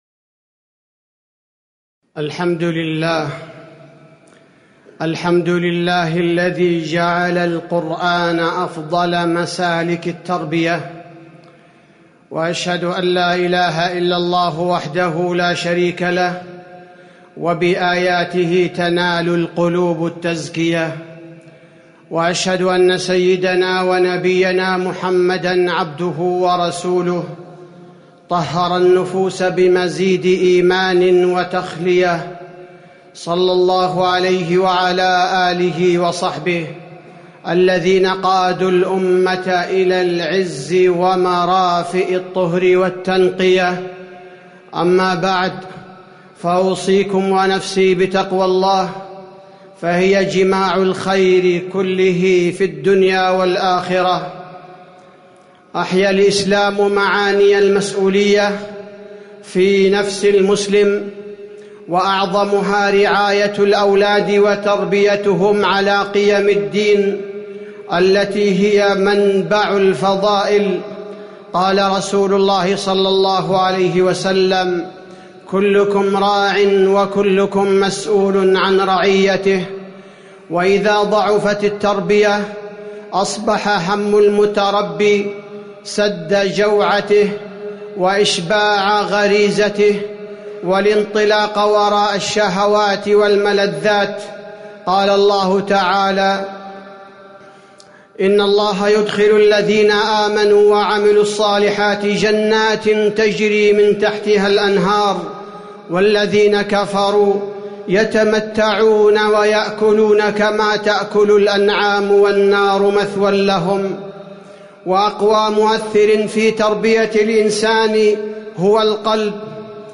تاريخ النشر ٢٣ جمادى الأولى ١٤٣٩ هـ المكان: المسجد النبوي الشيخ: فضيلة الشيخ عبدالباري الثبيتي فضيلة الشيخ عبدالباري الثبيتي مظاهر الحب في تربية الأبناء